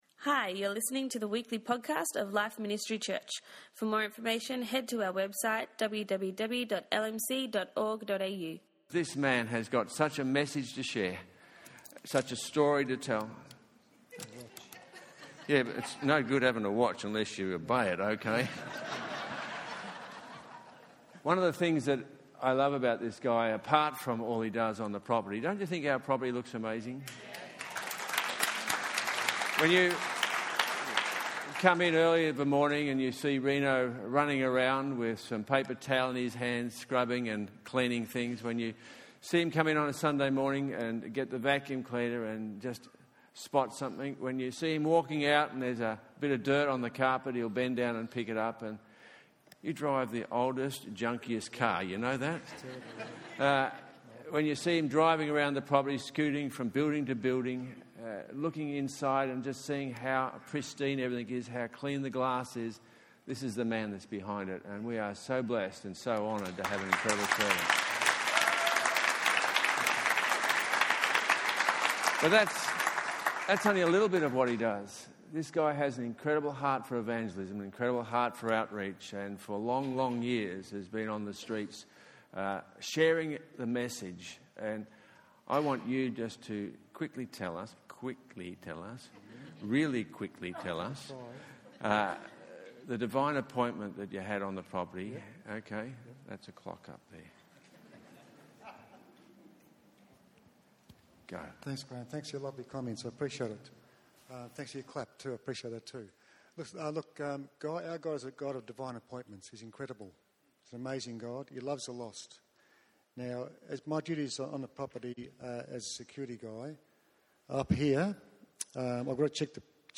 Testimonies
We had the privilege of hearing from some of the members of our church. Their testimonies will both encourage and inspire you to trust God and His leading in life.